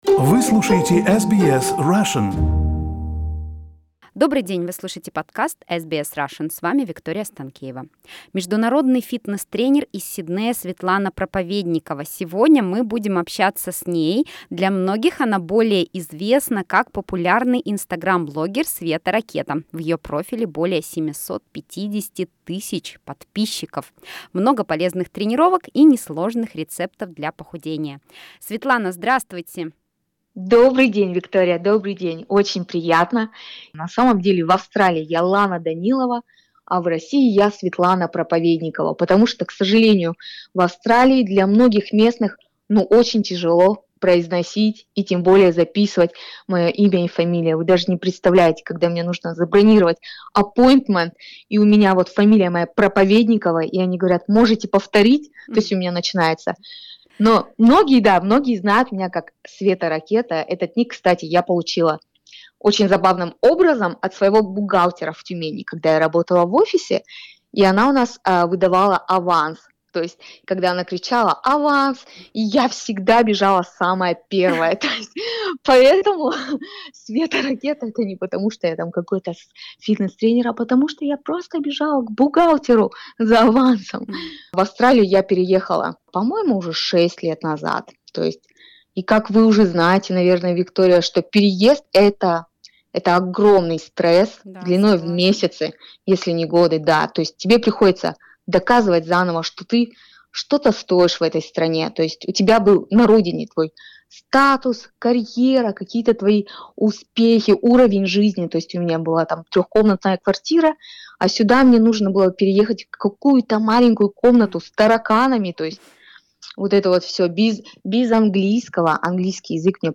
Слушайте в нашем интервью